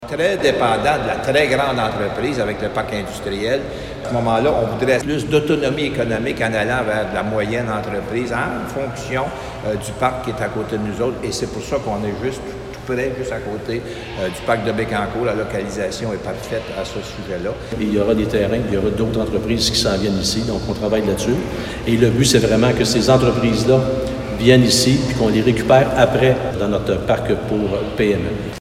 Le maire s’est réjoui de cette réalisation qui est projetée depuis 2015 et qui se veut le premier jalon de ce parc industriel destiné aux PME.